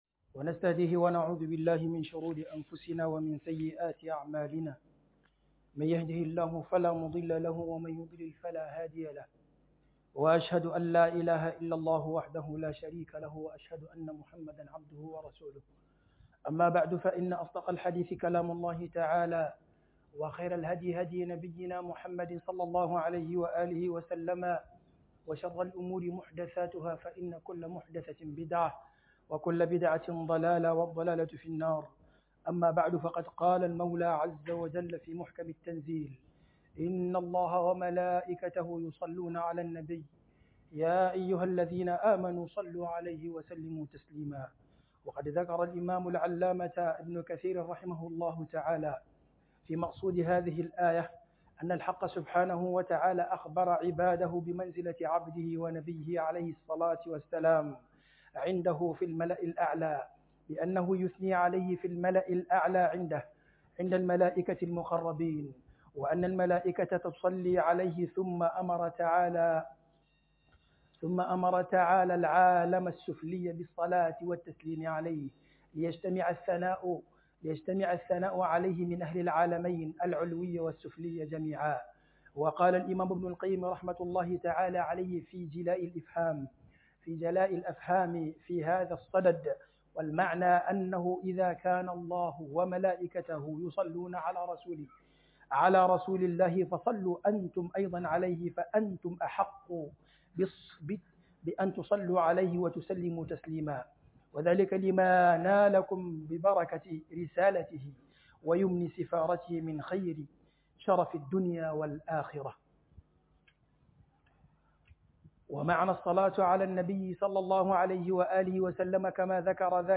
FALALAR SALATIN ANNABI S.A.W - KHUDUBA